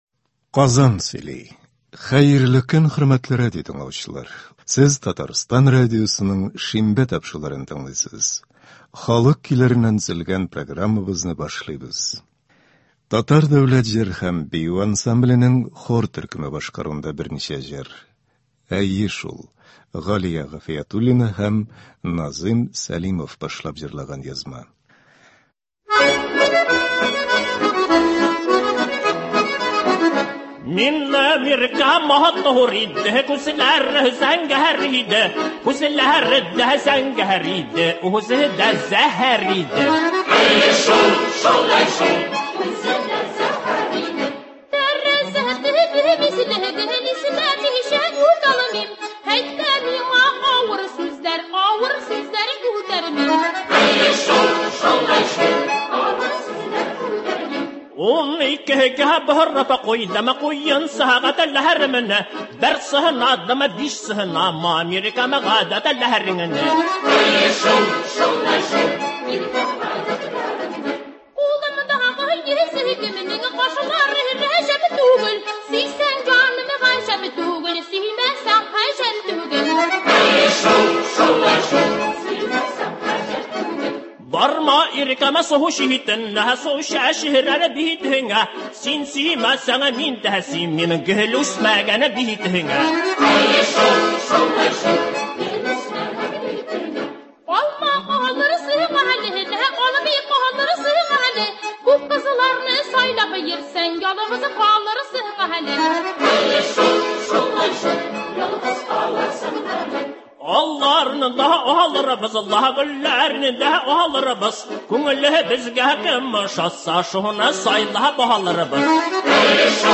Татар халык көйләре (06.08.22)
Бүген без сезнең игътибарга радио фондында сакланган җырлардан төзелгән концерт тыңларга тәкъдим итәбез.